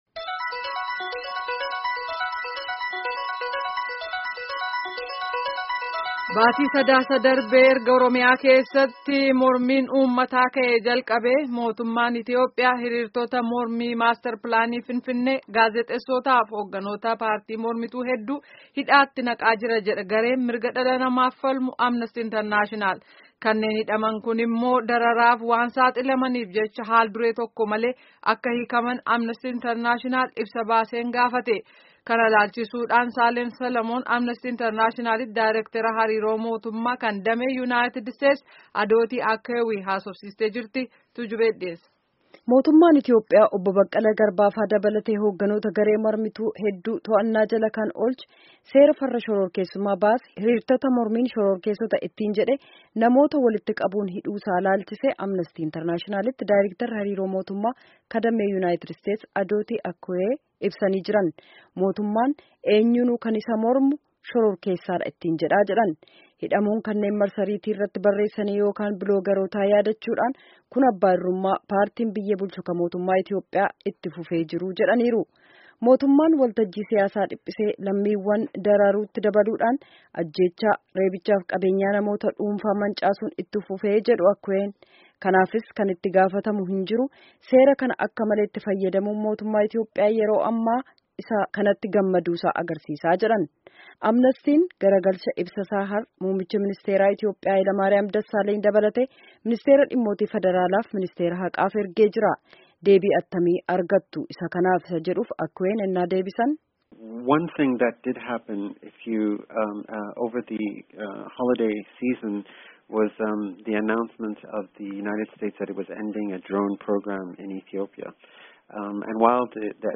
Gabaasni sagalee kunooti